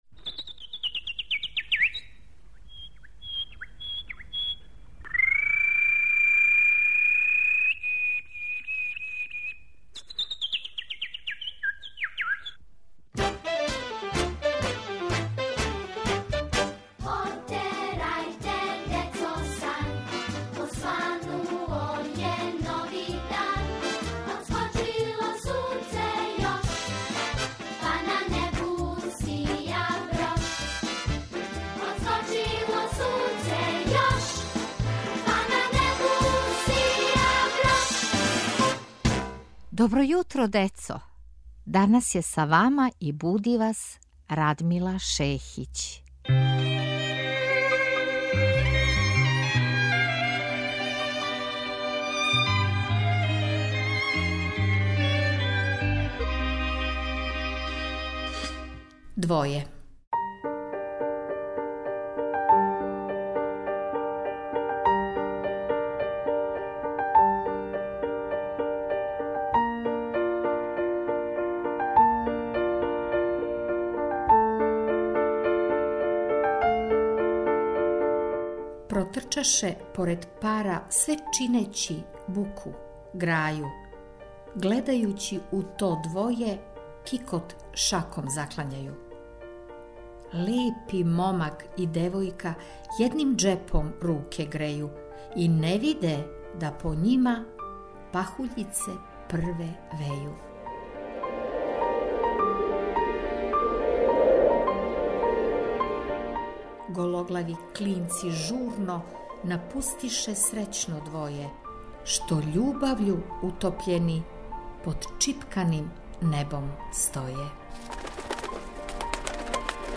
Поезија